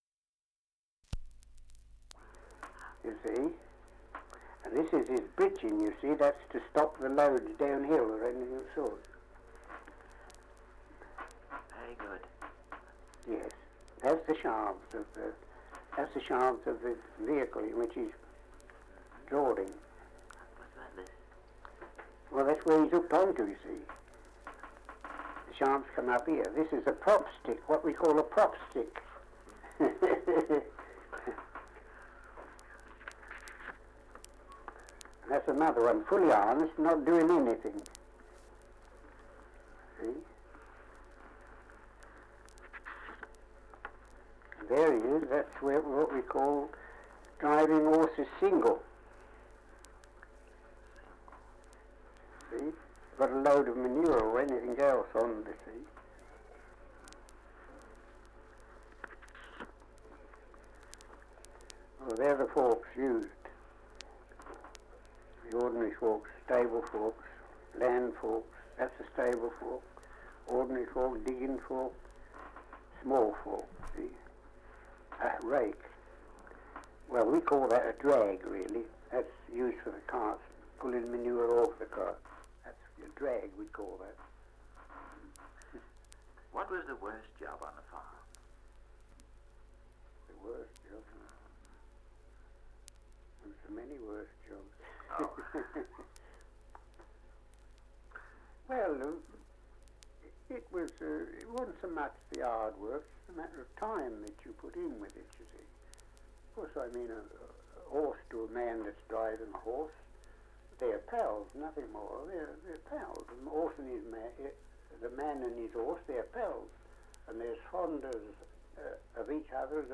Survey of English Dialects recording in Harmondsworth, Middlesex
78 r.p.m., cellulose nitrate on aluminium